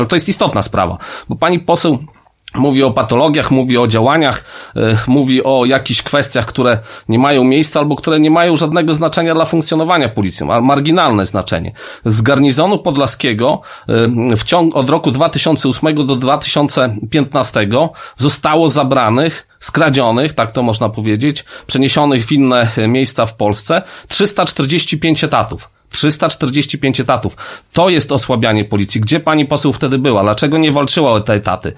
Do anonimów i wyników kontroli w podlaskiej policji odniósł się w poniedziałek (26.11) na antenie Radia 5 nadinspektor Daniel Kołnierowicz, Komendant Wojewódzki Policji w Białymstoku.